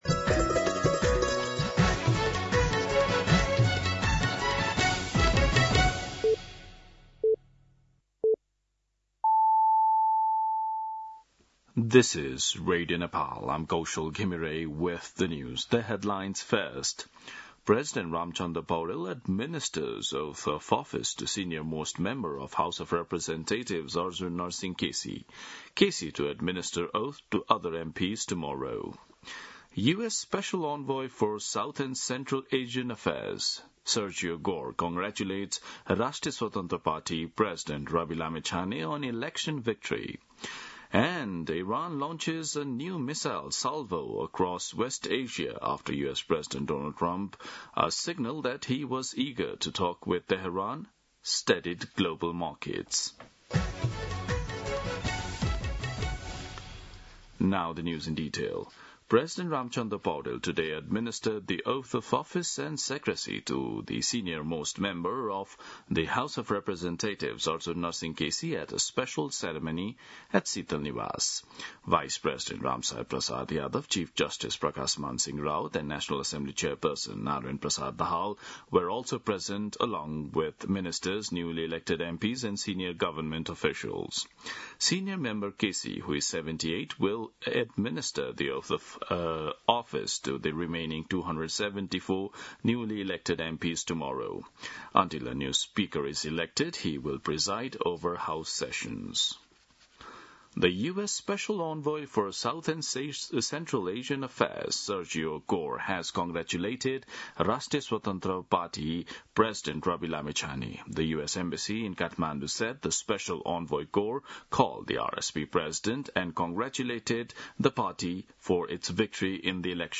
2pm-English-News.mp3